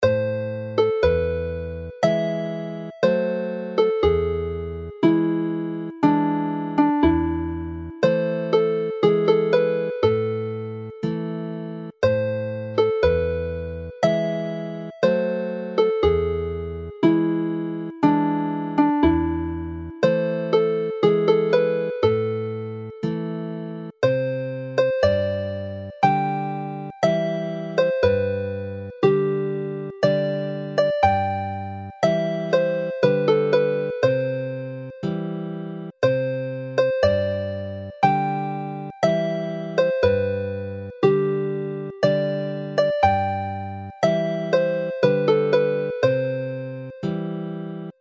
starting slow and melancholy
mournful